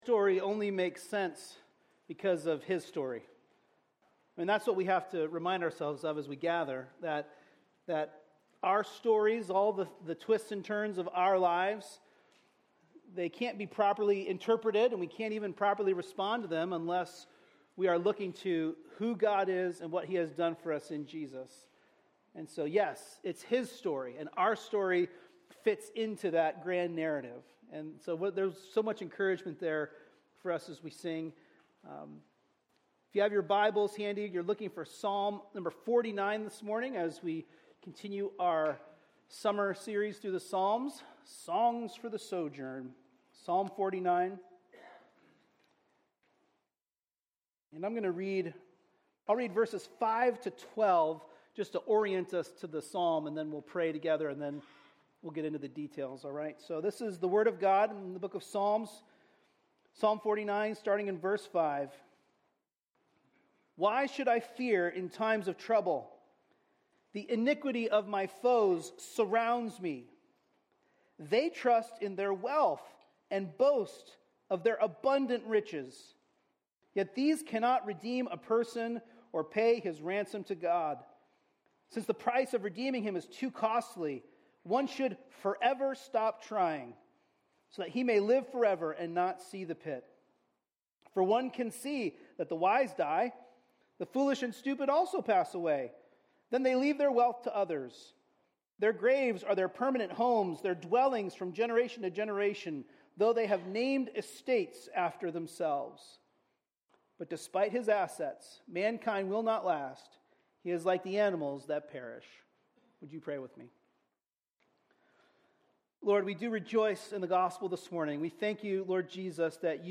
A message from the series "Psalms." In Psalm 49 we learn that Jesus dies what money never could. He redeems us from death and releases us from the worship of money.